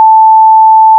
Building (5): chest, door_open, door_close, hammer, repair
**⚠  NOTE:** Music/SFX are PLACEHOLDERS (simple tones)
repair.wav